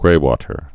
(grātər, -wŏtər)